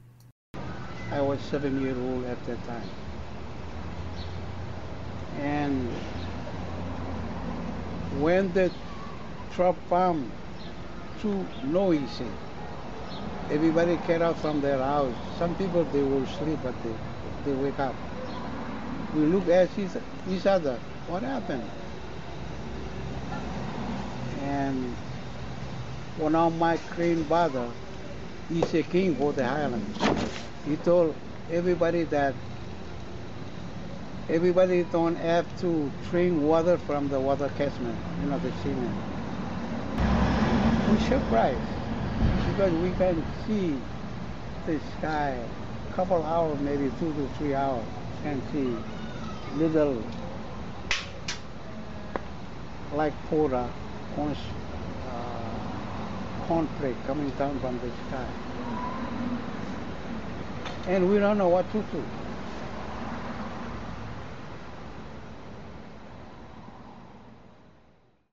Here is the only one I could find, in this case of a Marshallese elder relating his experience of the disastrous Castle Bravo test of 1954: